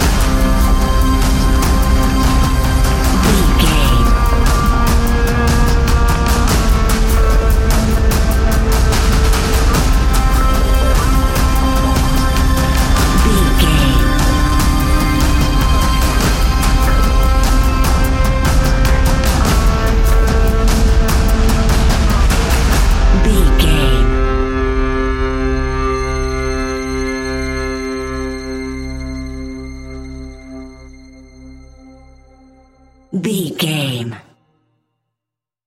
Fast paced
In-crescendo
Ionian/Major
industrial
dark ambient
EBM
drone
synths